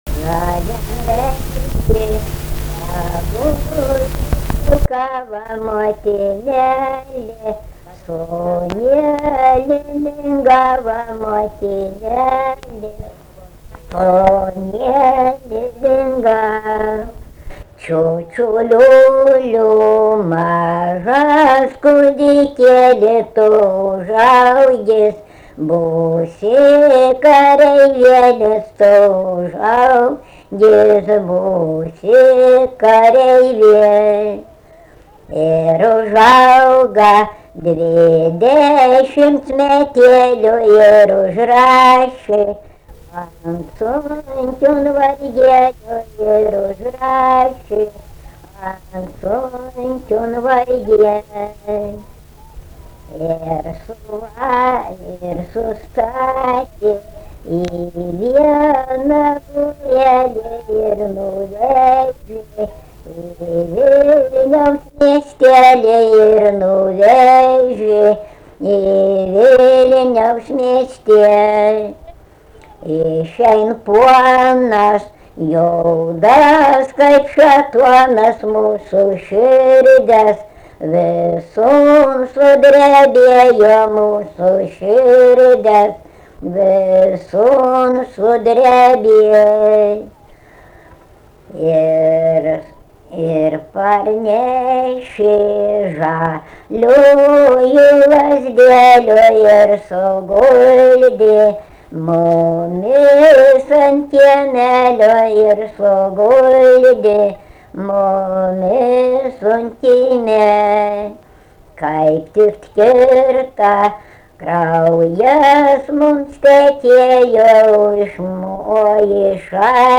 Dalykas, tema daina
Erdvinė aprėptis Barvydžiai
Atlikimo pubūdis vokalinis